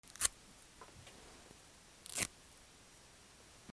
バナナの皮をちぎる
『ミシ』